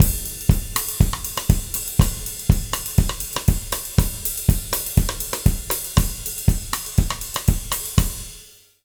120ZOUK 09-R.wav